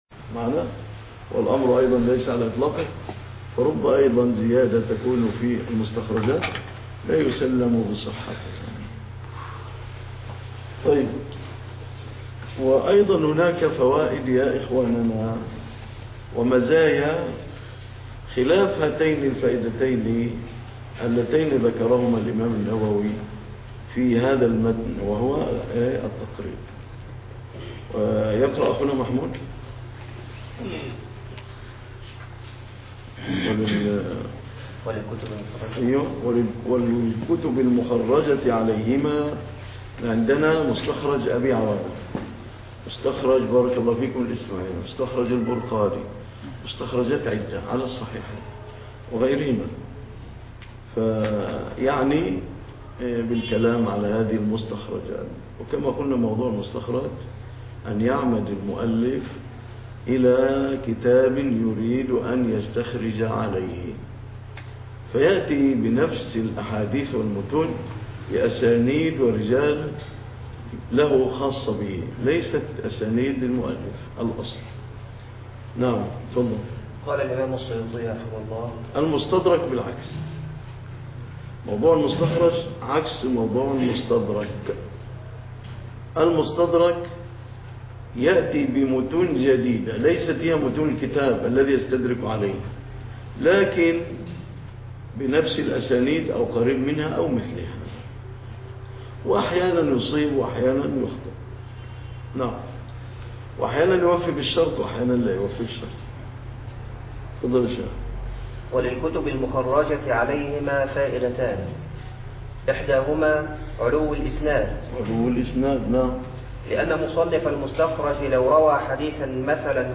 الدرس 016 (شرح كتاب تدريب الراوى